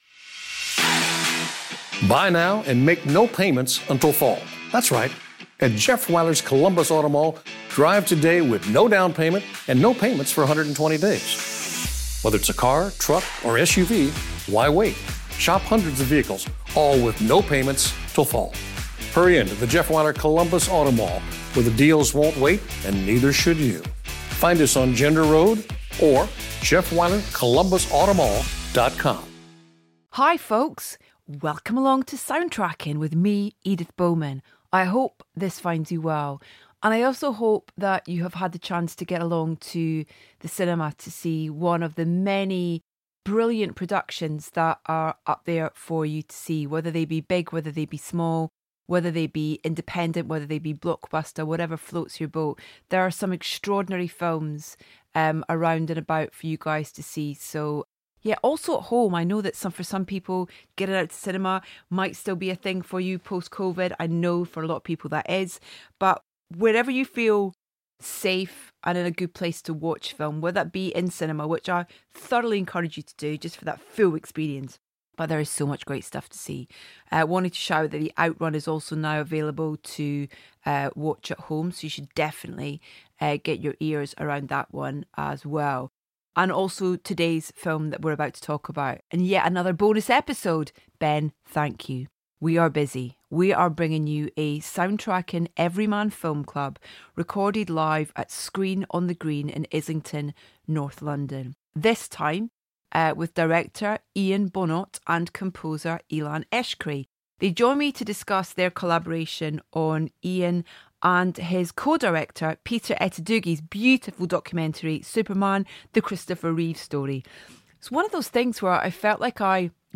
In yet another bonus episode - boy, are we busy - we're bringing you a Soundtracking Everyman Film Club, recorded live at Screen on the Green in Islington, north London.